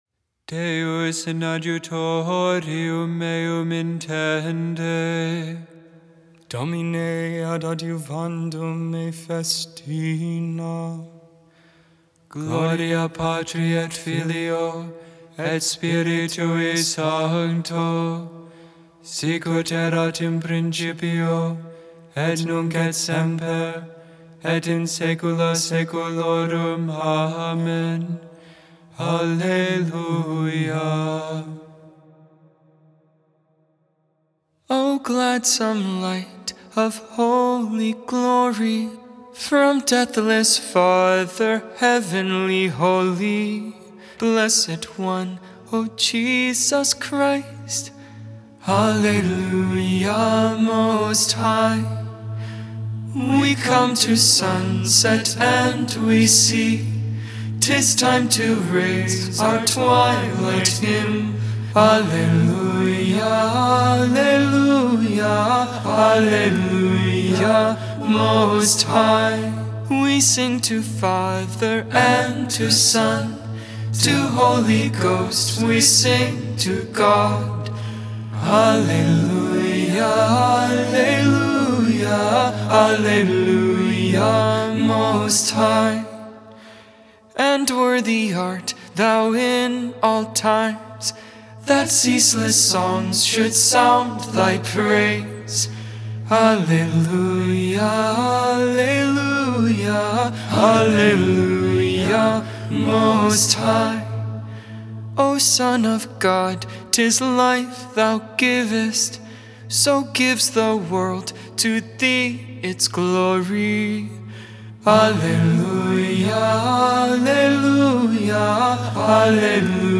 Psalm 30 (Gregorian tone 6) Psalm 32 (Gregorian tone 5)
Magnificat (Gregorian tone 1f)